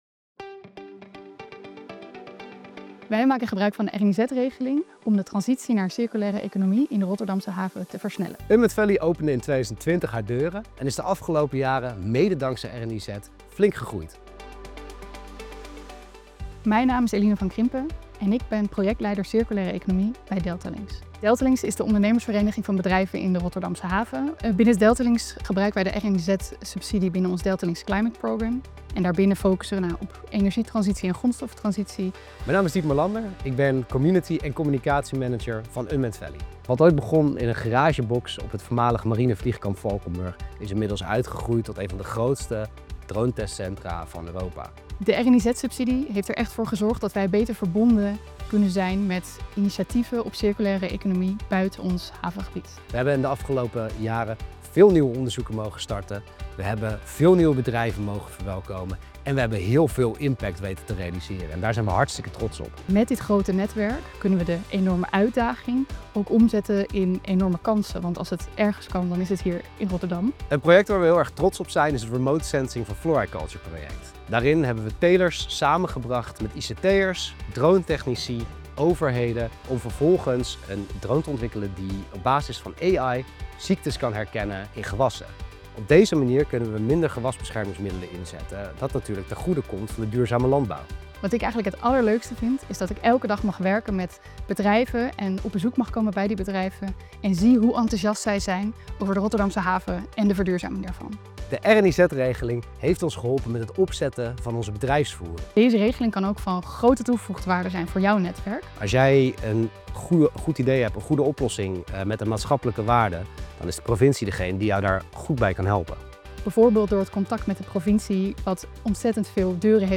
audiodescriptie_final_rniz.mp3